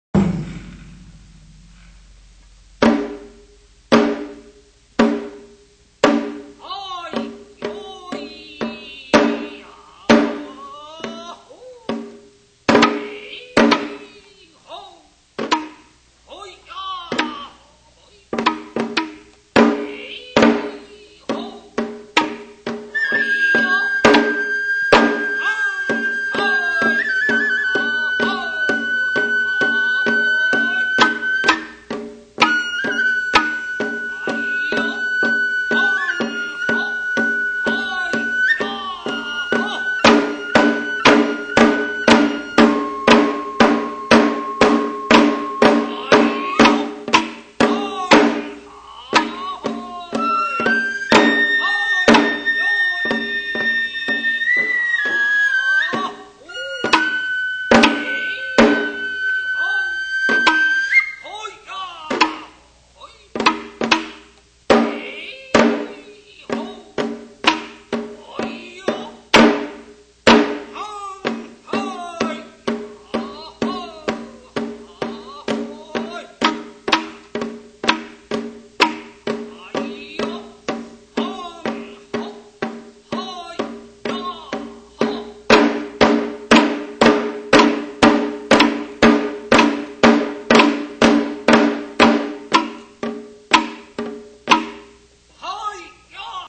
尾張地方の山車囃子は、能楽を基本に編曲された楽曲が多く、主に大太鼓、締太鼓、小鼓、そして笛（能管と篠笛）によって演奏されます。
人形囃子前半
からくり人形（倒立）の演技の際に演奏される曲．(※)